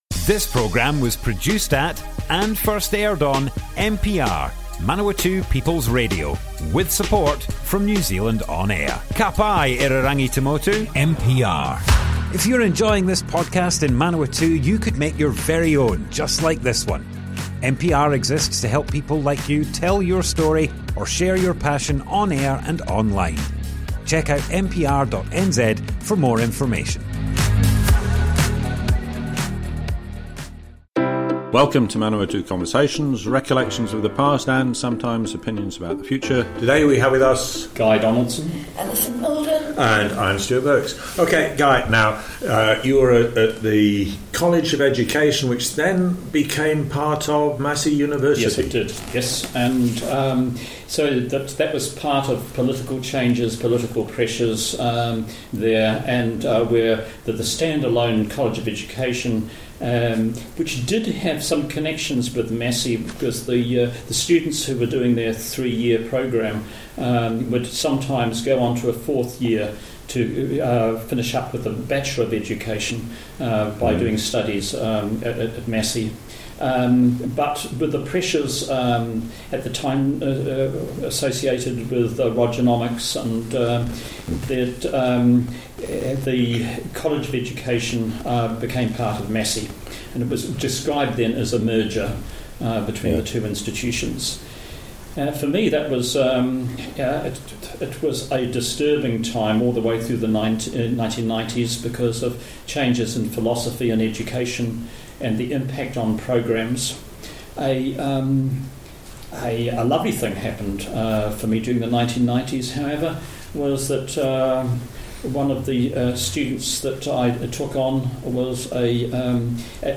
Manawatu Conversations More Info → Description Broadcast on Manawatu People's Radio, 30th April 2024.
oral history